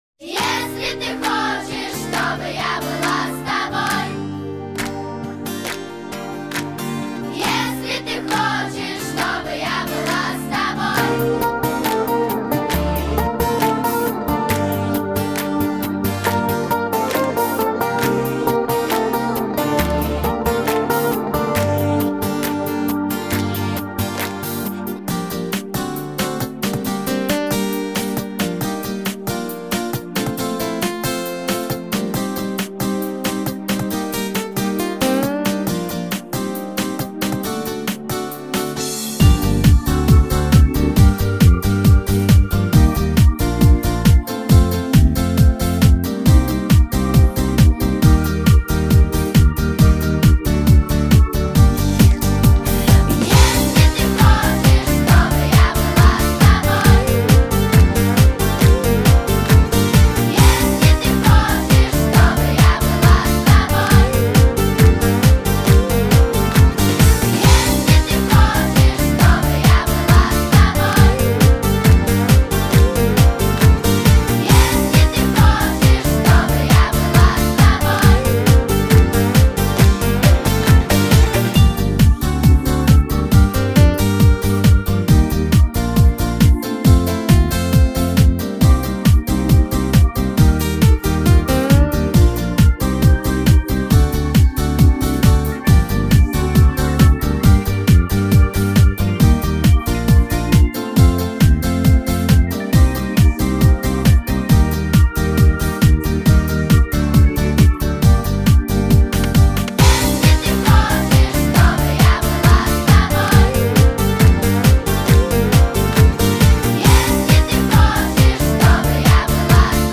Жанр: Минусовка